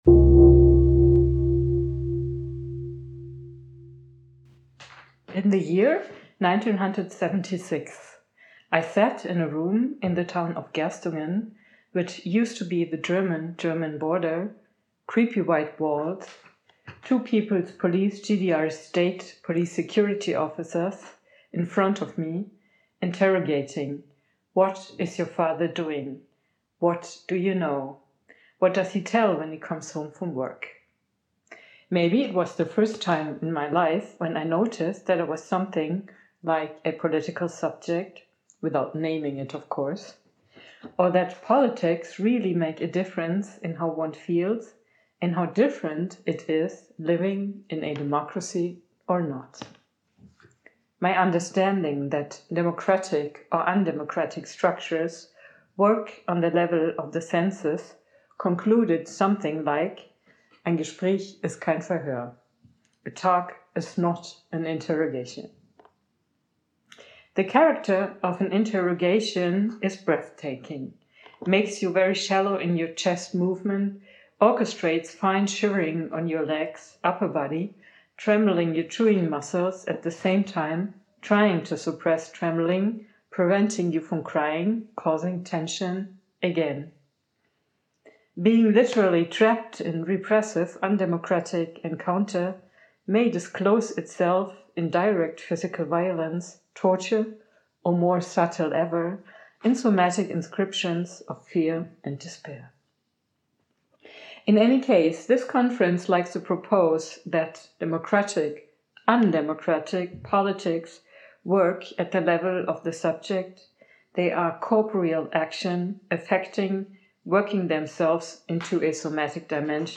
keynote_5.mp3